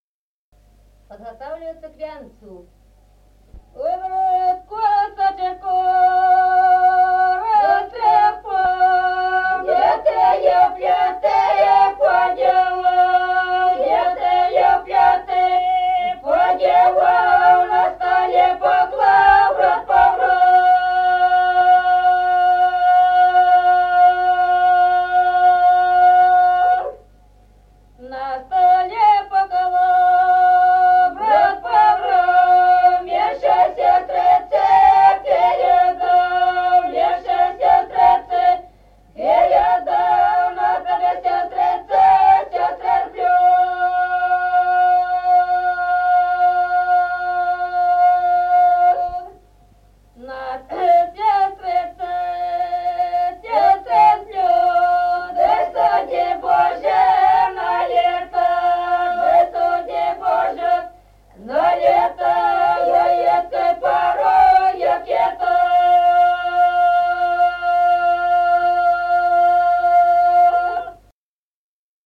Песни села Остроглядово. А брат косочку растрепал.